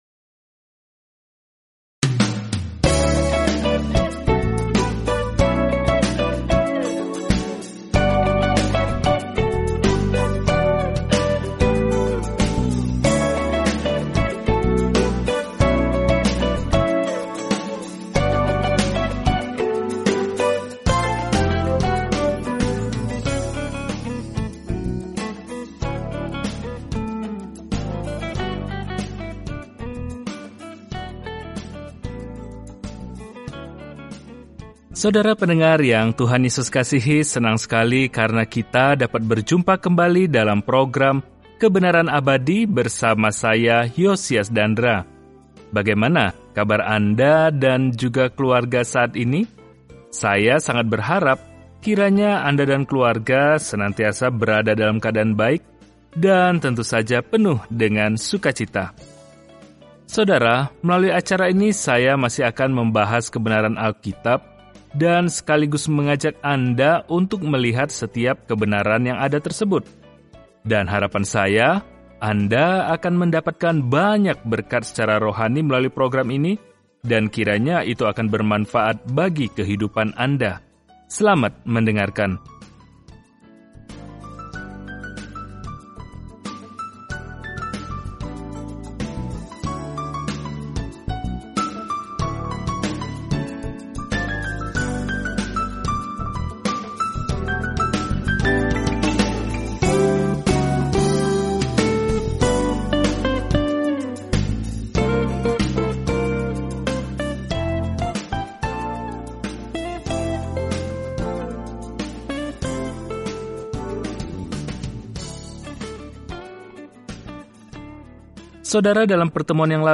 Firman Tuhan, Alkitab Maleakhi 3:9-16 Hari 12 Mulai Rencana ini Hari 14 Tentang Rencana ini Maleakhi mengingatkan Israel yang terputus bahwa dia memiliki pesan dari Tuhan sebelum mereka mengalami keheningan yang lama – yang akan berakhir ketika Yesus Kristus memasuki panggung. Jelajahi Maleakhi setiap hari sambil mendengarkan pelajaran audio dan membaca ayat-ayat tertentu dari firman Tuhan.